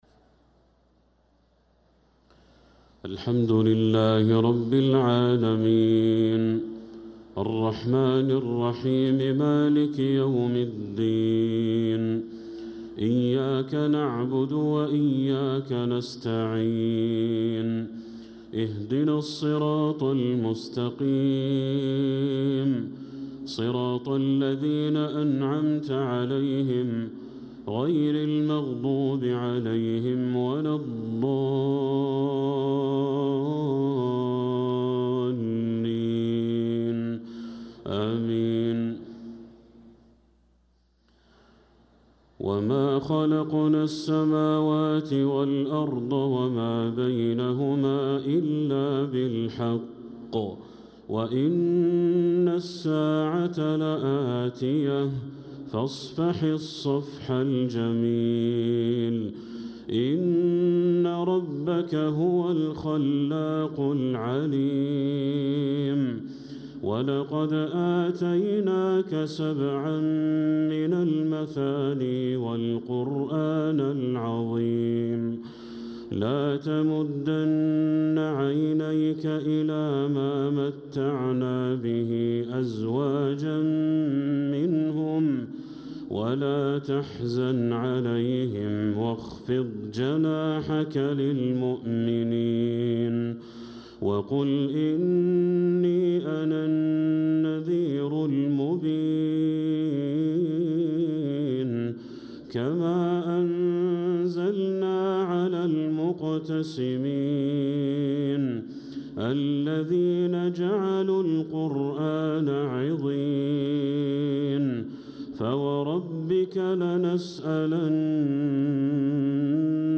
صلاة المغرب للقارئ بدر التركي 21 رجب 1446 هـ
تِلَاوَات الْحَرَمَيْن .